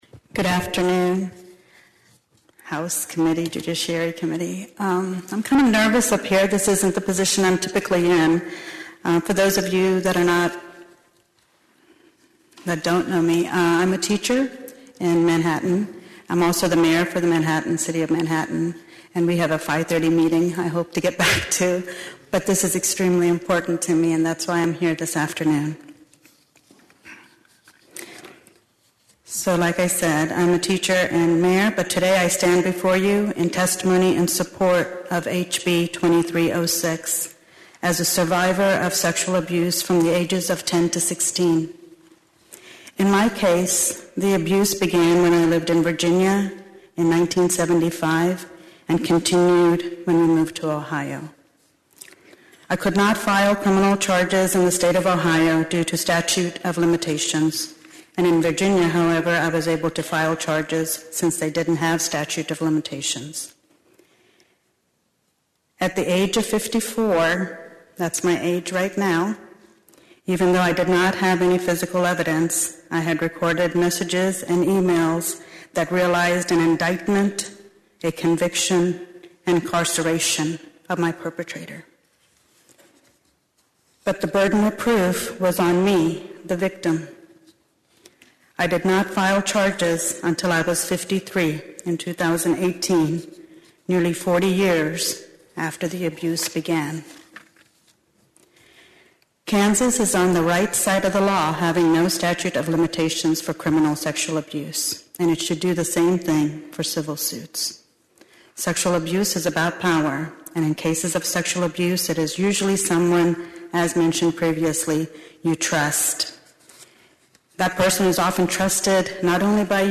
TOPEKA — Manhattan Mayor and U.S. Senate candidate Usha Reddi testified Tuesday in favor of a bill that would remove the statute of limitations for reporting childhood sexual abuse in civil suits.
Listen to Mayor Usha Reddi’s full testimony below.